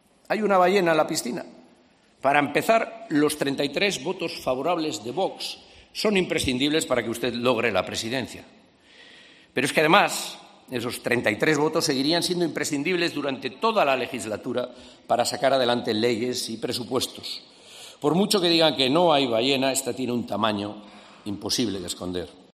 Aitor Esteban, portavoz del PNV